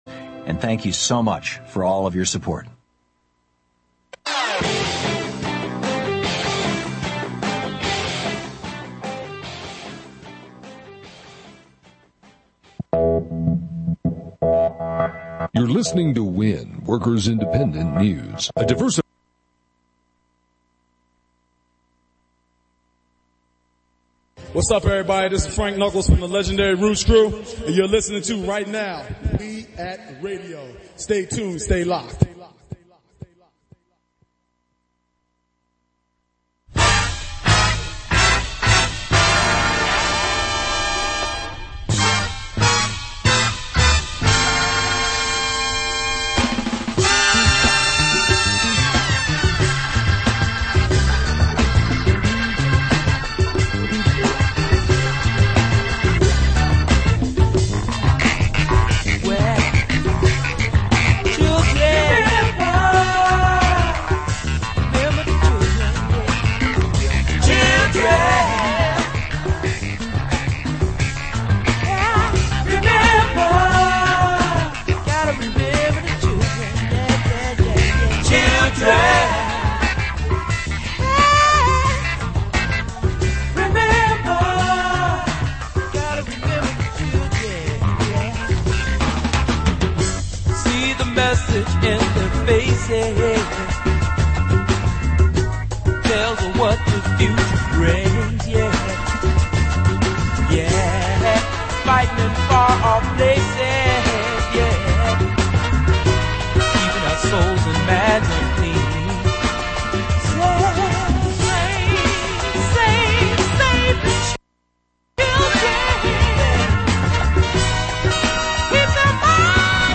Also on this week’s program — Feature: James Baldwin Archives and National Library Week Segment 2: Ballou SHS and “The Contract Song” The Education Town Hall broadcasts from Historic Anacostia in DC on We Act Radio, Thursdays at 11:00 a.m. Eastern Listen live via TuneIn .